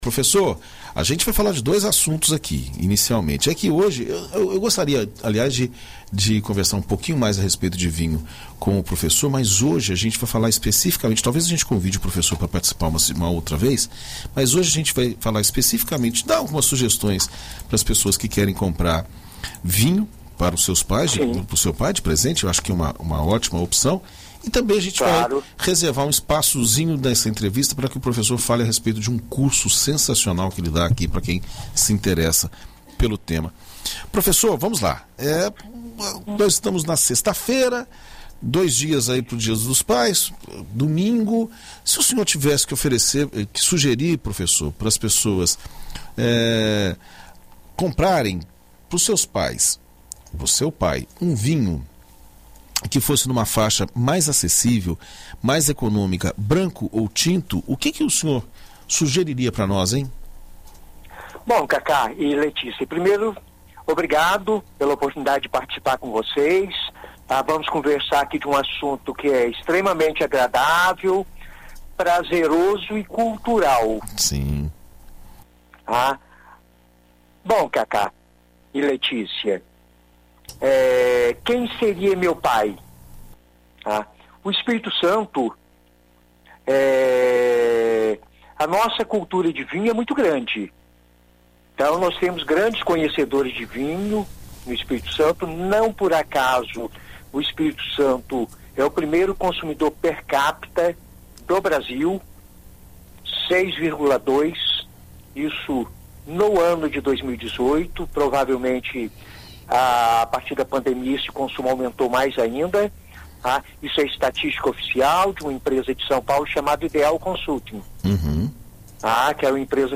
Em entrevista à BandNews FM Espírito Santo nesta sexta-feira (06)